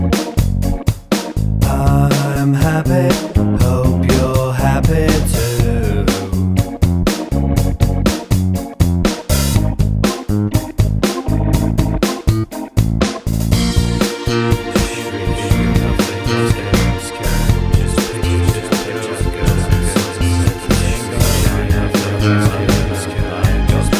no guitars Pop (1980s) 4:19 Buy £1.50